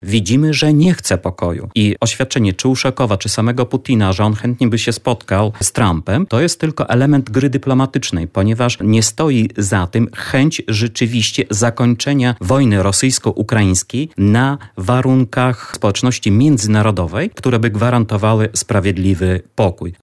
mówi gość porannej rozmowy Radia Lublin